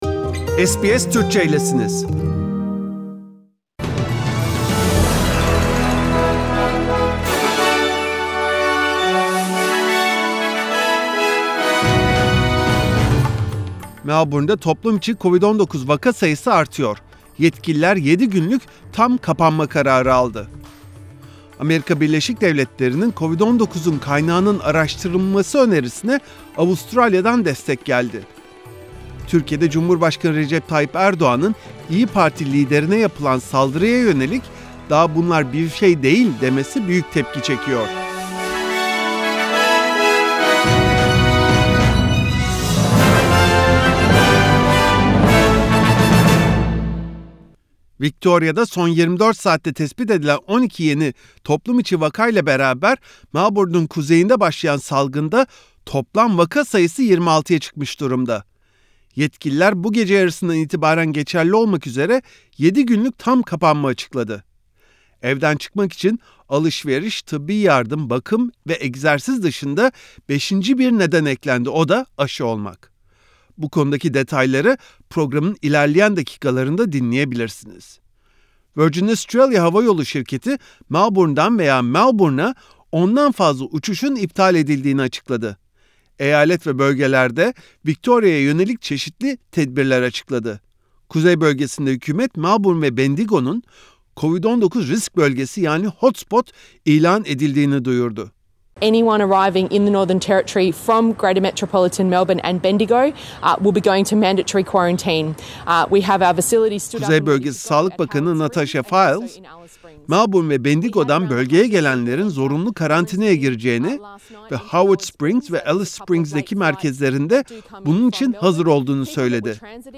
SBS Türkçe Haberler 27 Mayıs